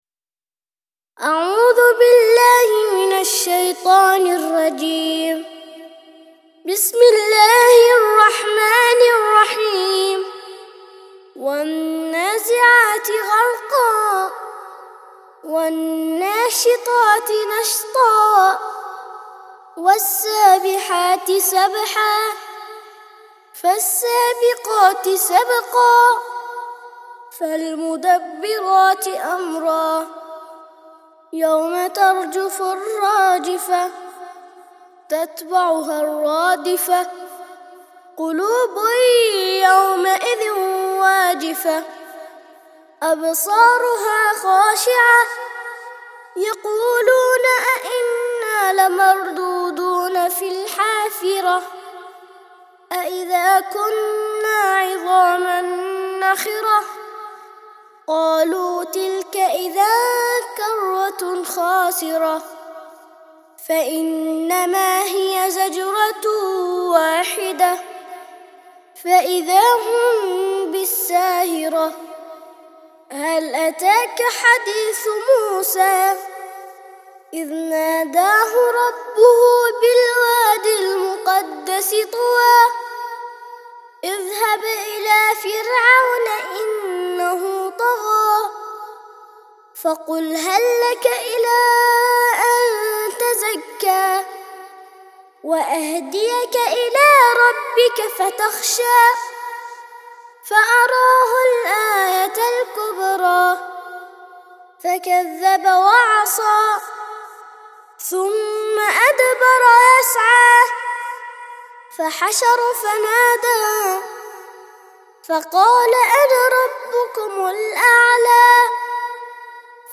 79- سورة النازعات - ترتيل سورة النازعات للأطفال لحفظ الملف في مجلد خاص اضغط بالزر الأيمن هنا ثم اختر (حفظ الهدف باسم - Save Target As) واختر المكان المناسب